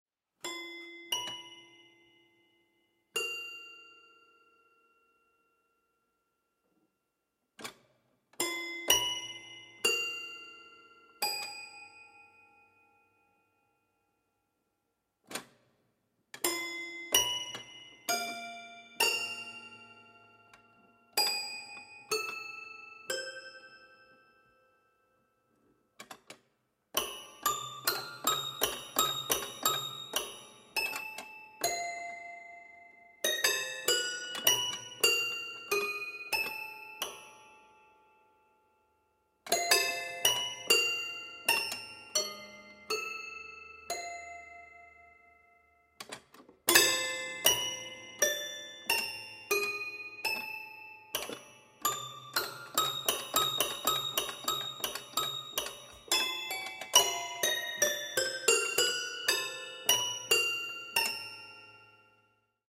boogie-woogie